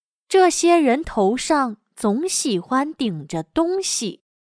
这些人头上总喜欢顶着东西。/Zhèxiē réntóu shàng zǒng xǐhuān dǐngzhe dōngxī./A esta gente siempre le gusta tener cosas en la cabeza.